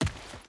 Footsteps / Dirt
Dirt Run 3.ogg